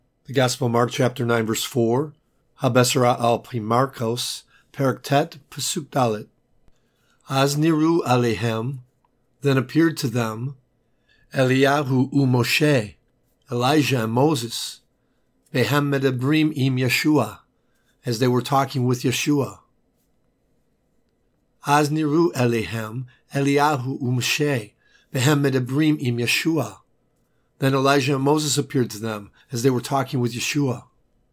Hebrew Lesson